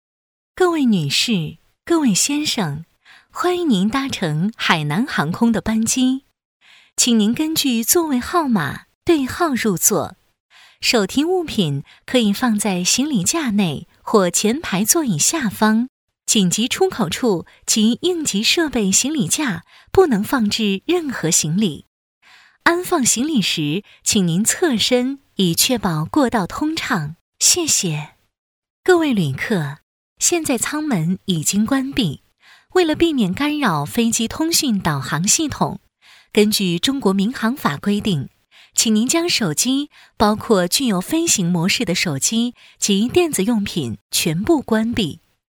女16-机场广播【海南航空安全须知】
女16-机场广播【海南航空安全须知】.mp3